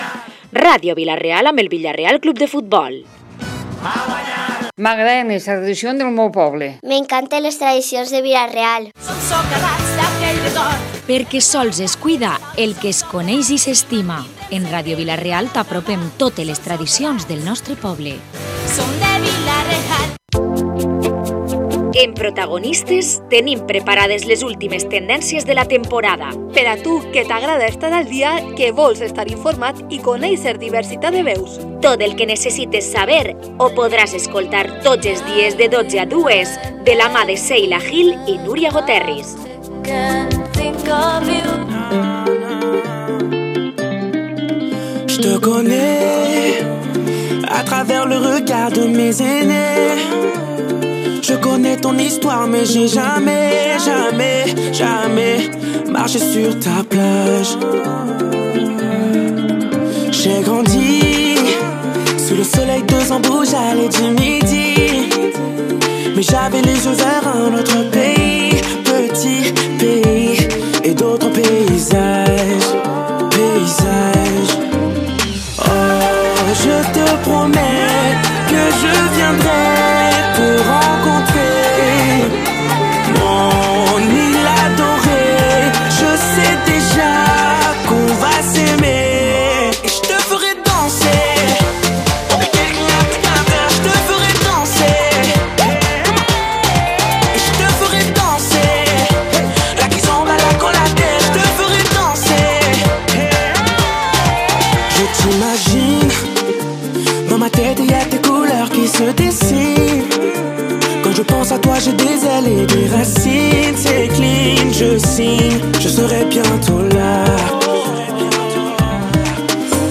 Además, hemos recibido a una decena de pequeños que nos han contado sus deseos para la llegada de los Reyes y han recibido la llamada especial del Rey Gaspar.